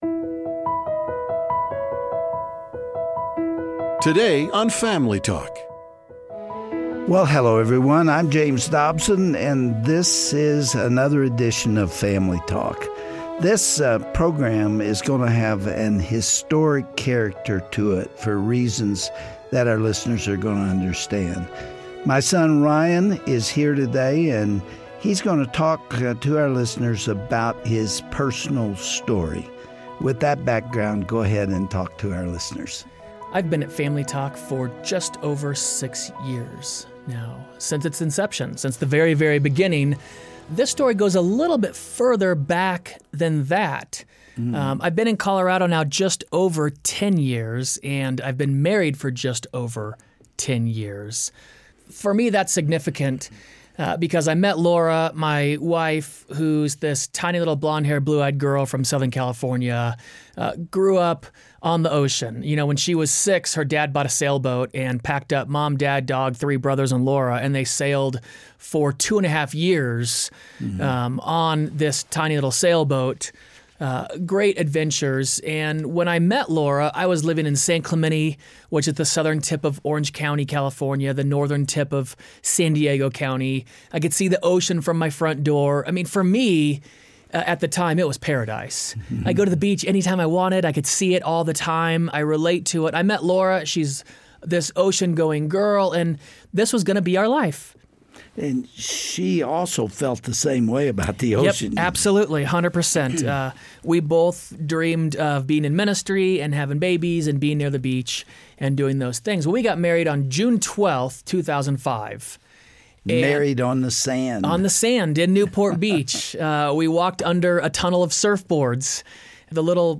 Farewell Interview